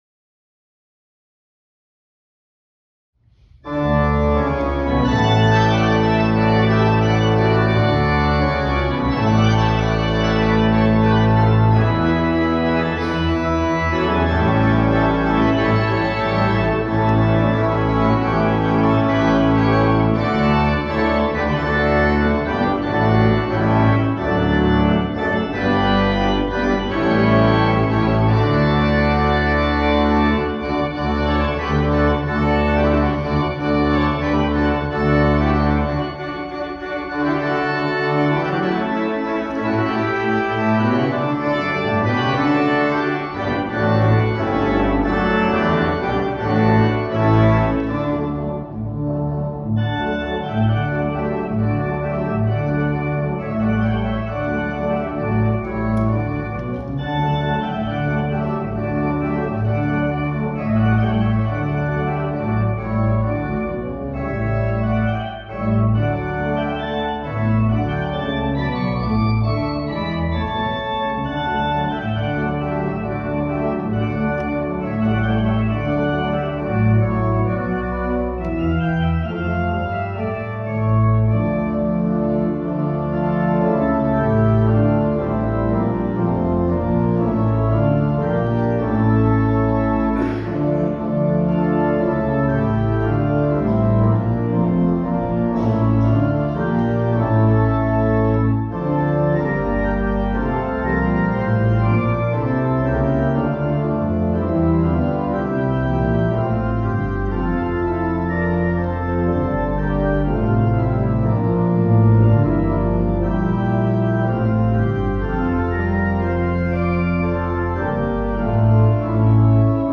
arranged for organ
Tidaholms Kyrka (Church), Tidaholm, Sweden